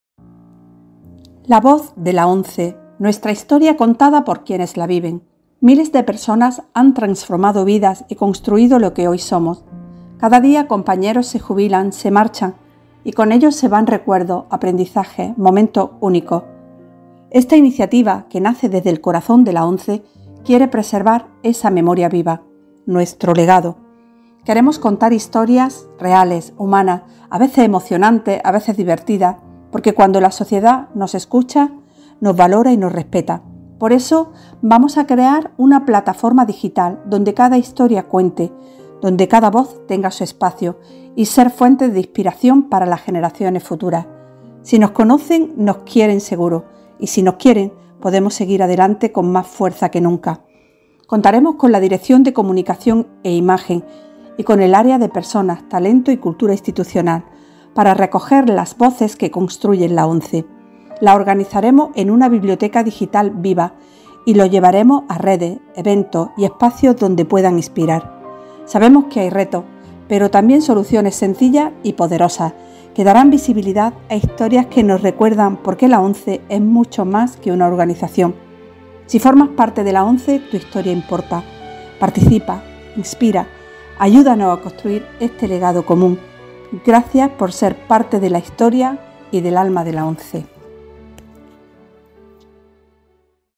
Presentación 'La voz de la ONCE'